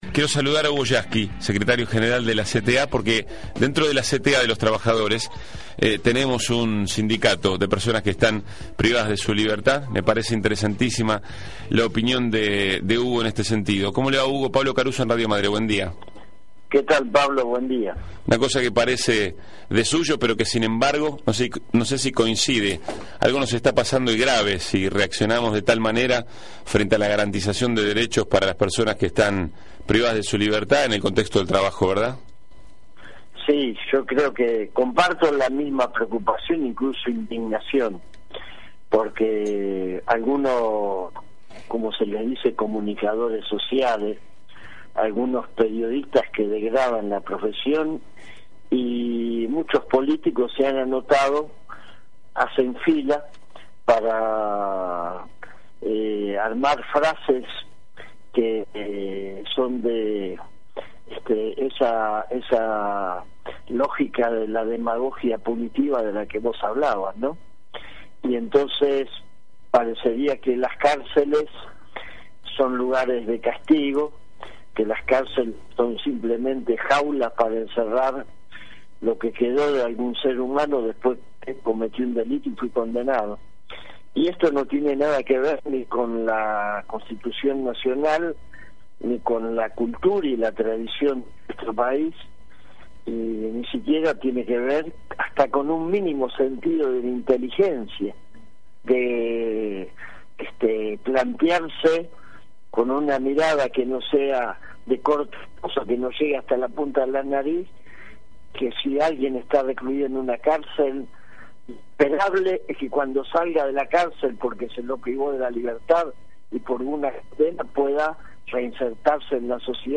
HUGO YASKY (entrevista) RADIO MADRE
hugoyasky_radio_madre.mp3